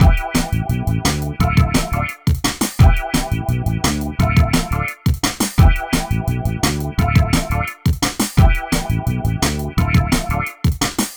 13 Breakdance-a.wav